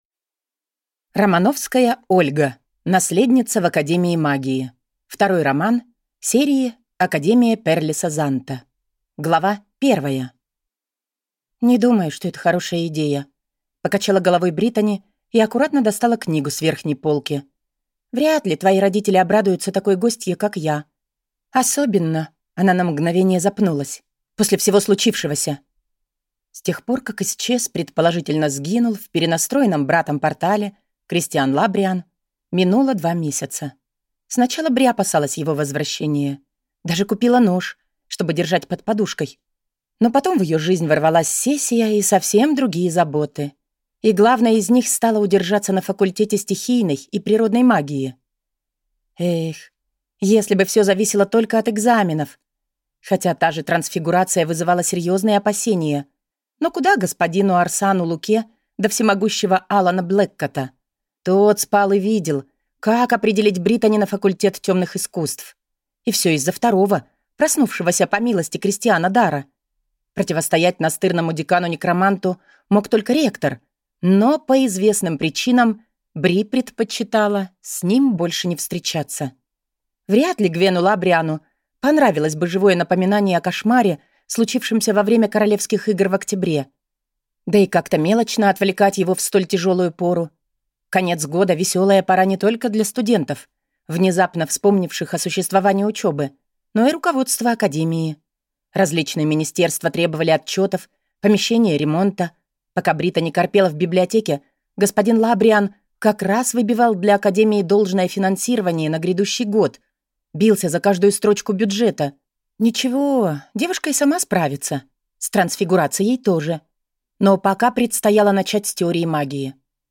Аудиокнига Наследница в академии магии | Библиотека аудиокниг